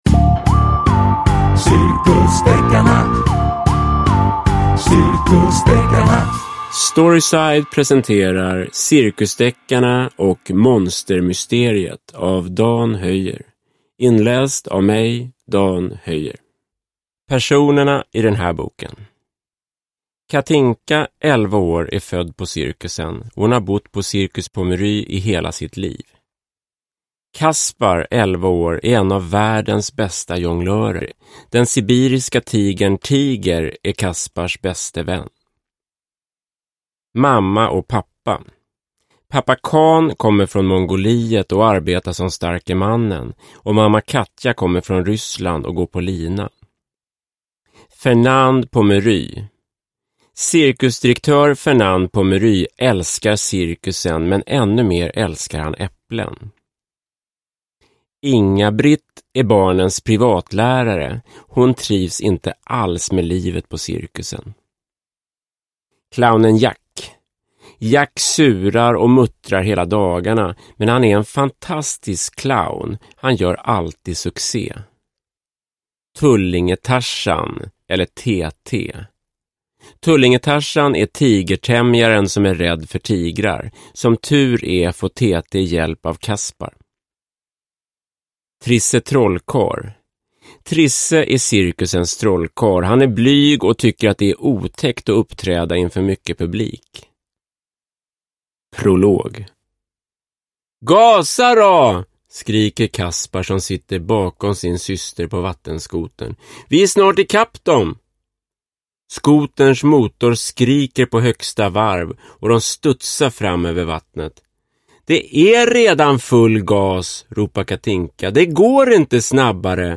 Cirkusdeckarna och monstermysteriet – Ljudbok – Laddas ner